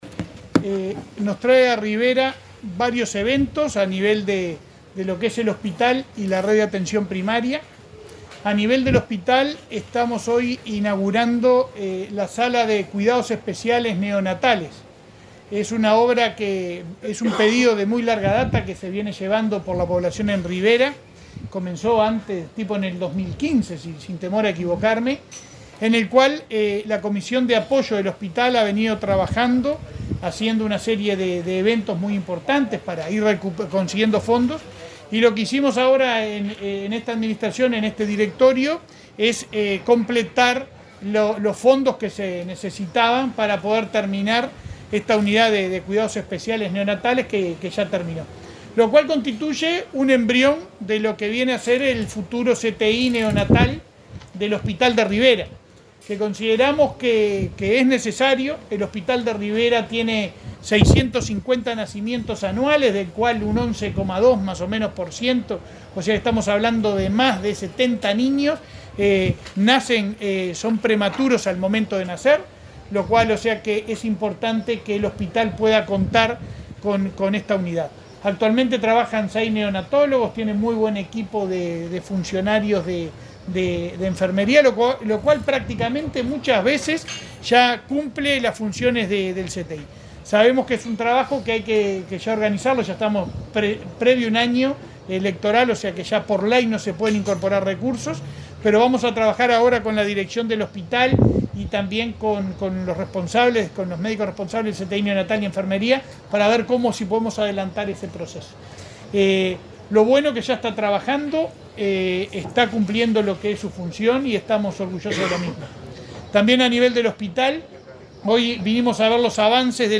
Conferencia de prensa del presidente de ASSE, Leonardo Cipriani
Este miércoles 1.°, el presidente de ASSE, Leonardo Cipriani, participó en una conferencia de prensa en el hospital de Rivera, en el marco de una